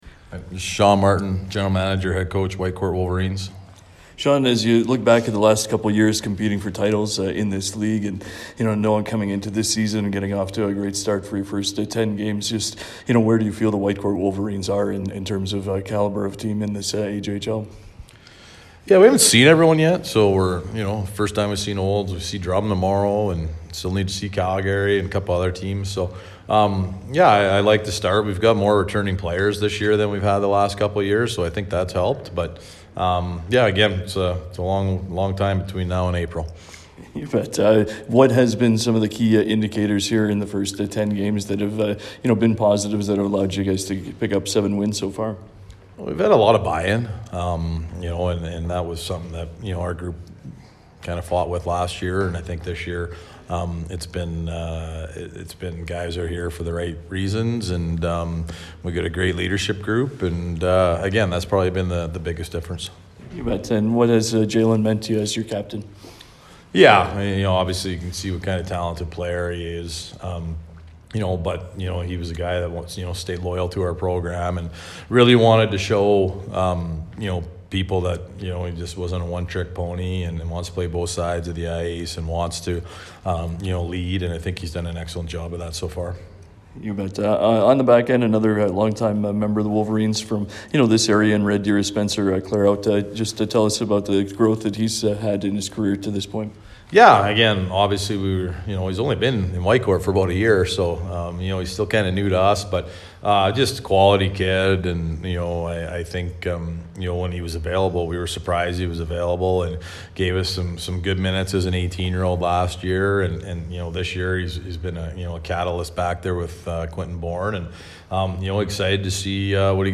pre-game audio